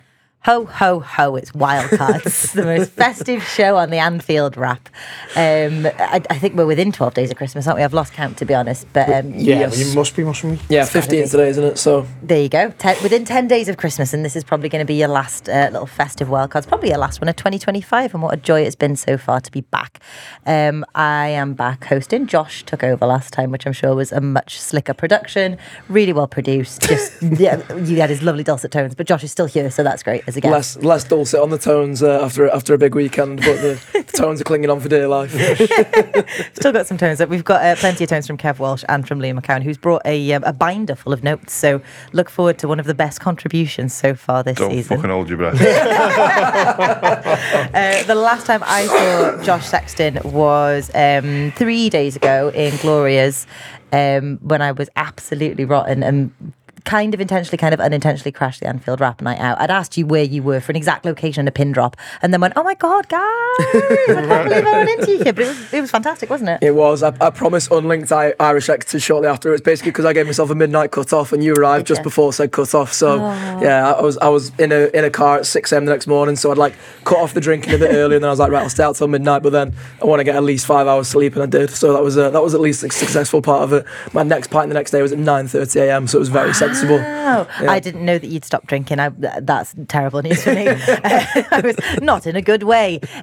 Wildcards, the show where YOU pick the questions.